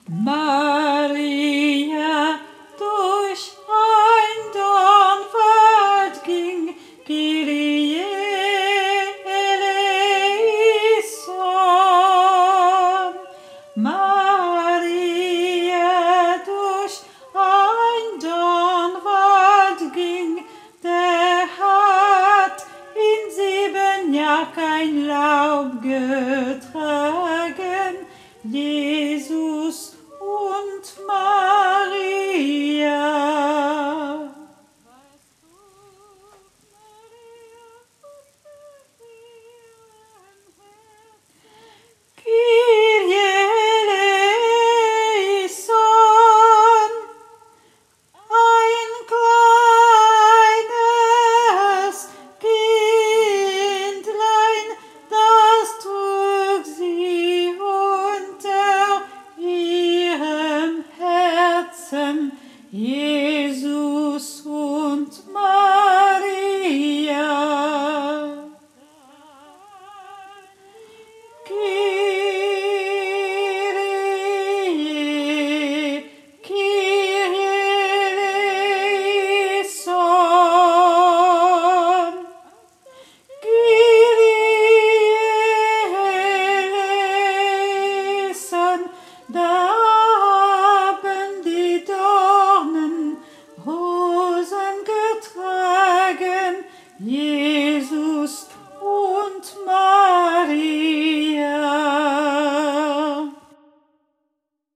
MP3 versions chantées
Alto